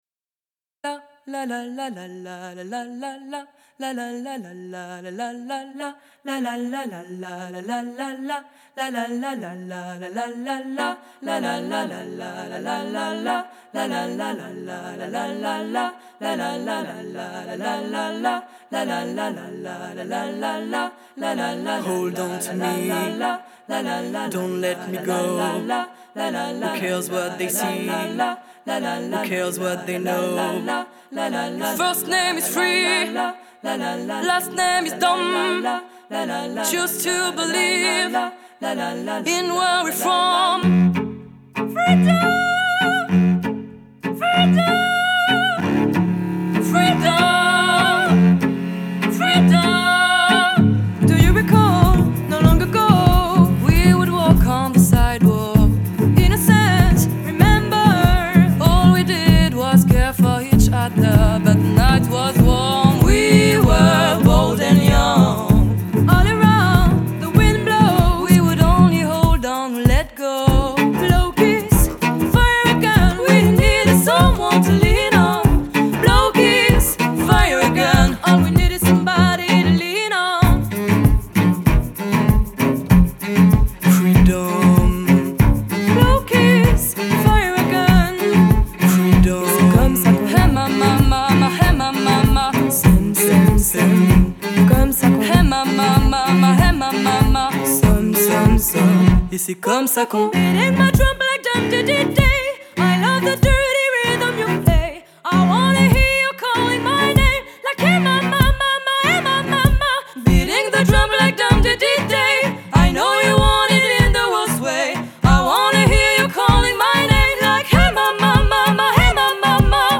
Строго, без излишеств – вокал, виолончель и барабан.
Genre: French Music, Pop